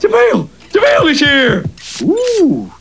Les sons pour signaler l'arrivée d'un e-mail
Et si vous laissiez à quelqu’un le soin de vous annoncer l’arrivée d’un nouveau message.